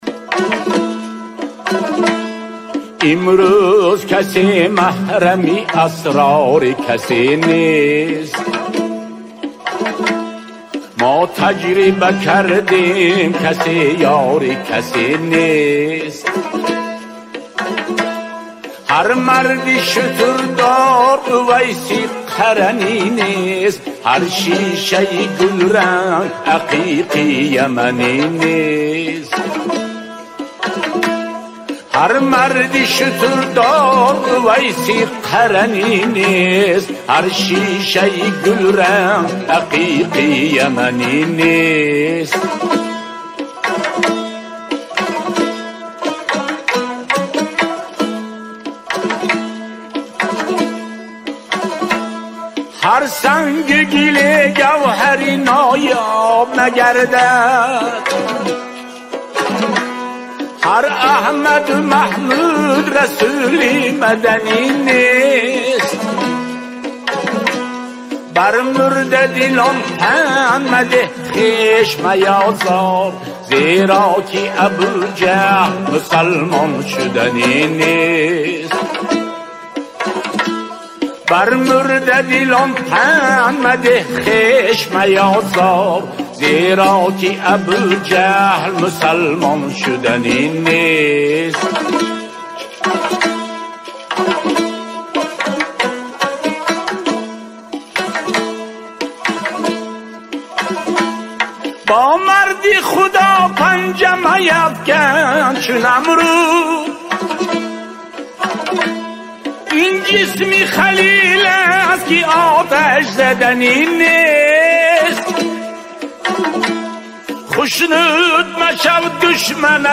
Маҳрами асрор бо садои хонандаи тоҷик